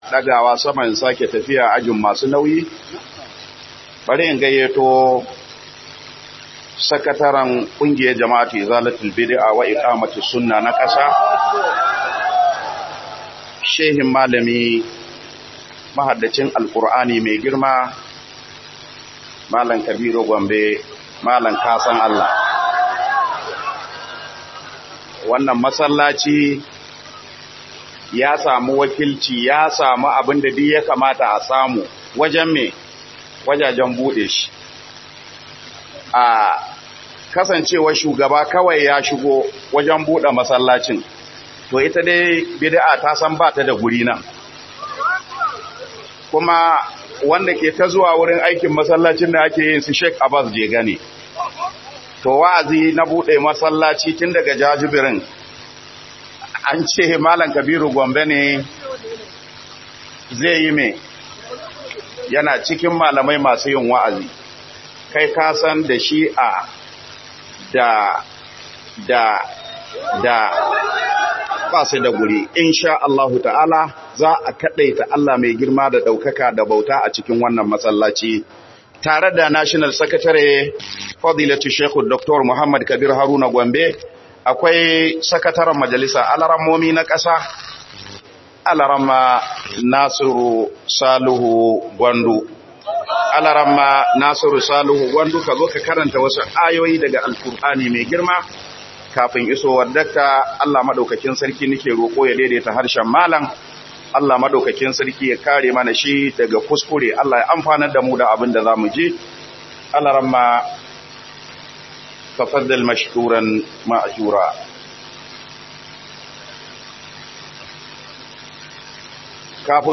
Muhimmancin kafa kungiya irin IZALA a musulunci Cigaban da kungiyar IZALA ta kawowa musulunci - MUHADARA